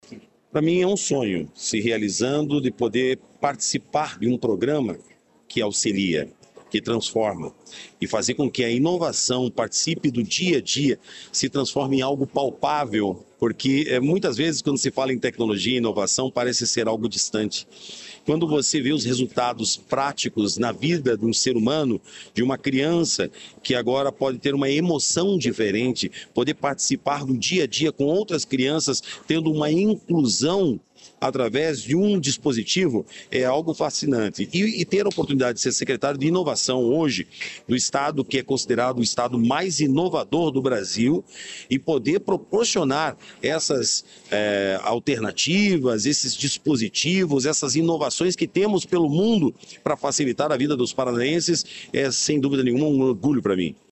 Sonora do secretário da Inovação, Modernização e Transformação Digital, Marcelo Rangel, sobre os óculos com inteligência artificial